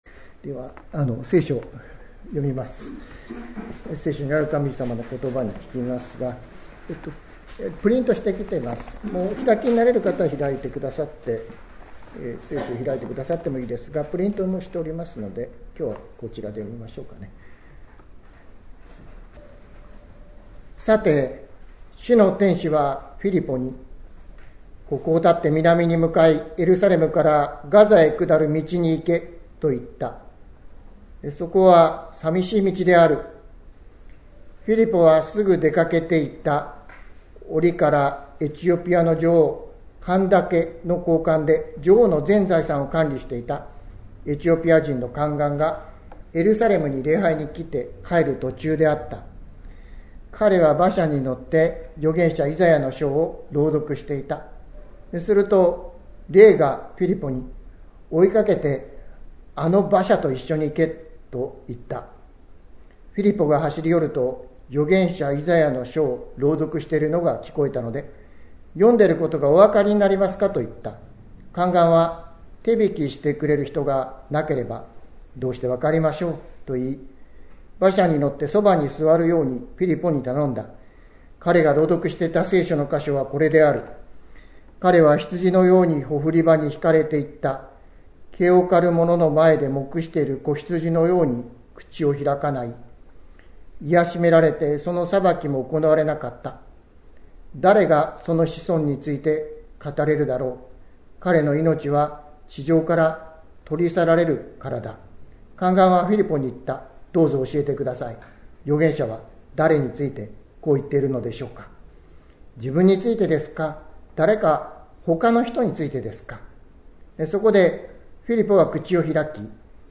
2024年11月17日朝の礼拝「道ありき」関キリスト教会
説教アーカイブ。